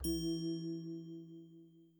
Tabla ding
bell ding sound effect free sound royalty free Sound Effects